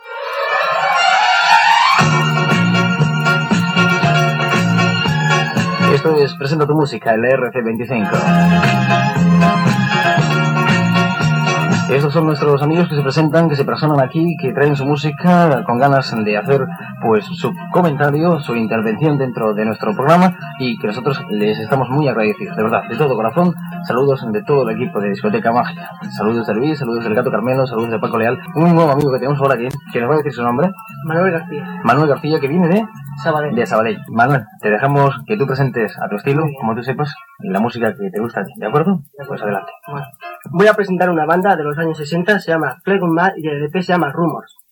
Identificació del programa, noms de l'equip, salutació a un oïdor del programa que presenta un tema musical.
Musical
FM